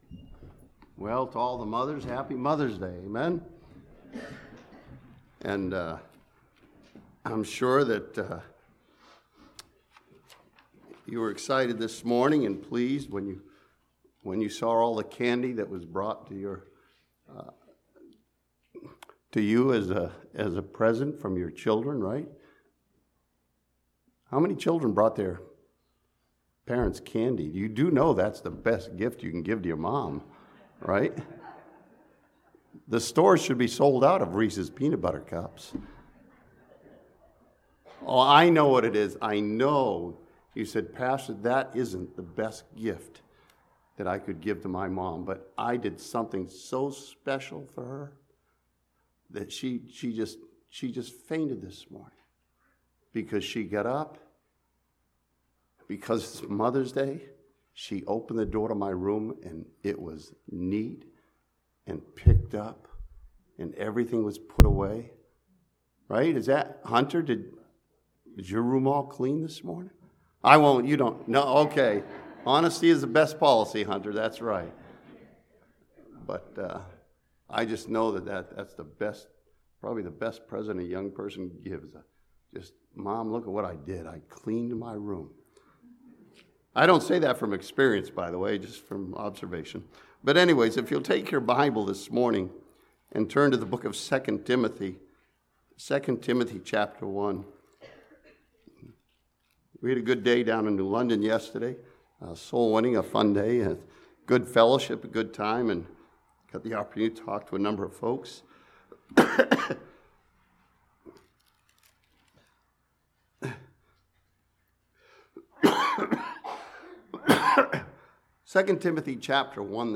This sermon from 2 Timothy 1 studies the unfeigned faith of Timothy's mother and grandmother.